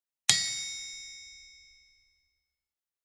На этой странице собраны звуки кузницы и работы с наковальней: ритмичные удары молота, звон металла, фоновый гул мастерской.
Звук удара молотком о сталь звонкий